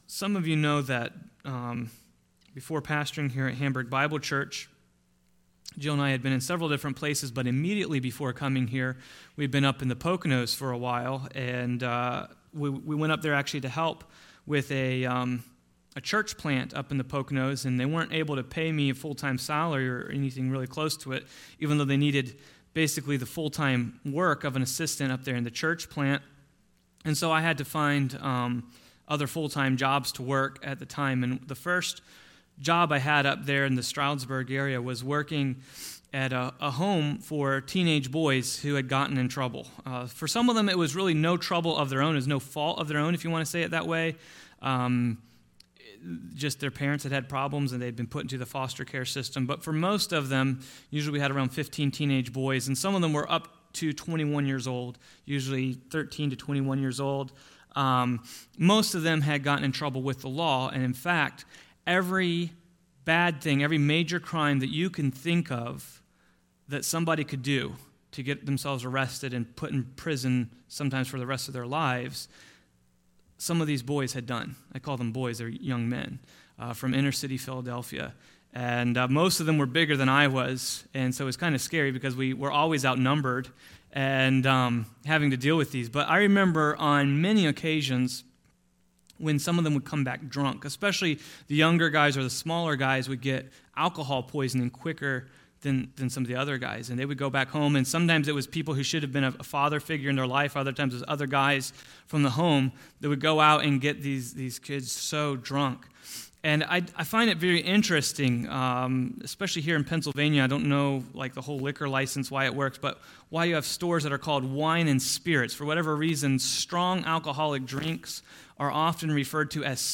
In this sermon we learn the difference between Holy Spirit baptism and being filled with the Holy Spirit.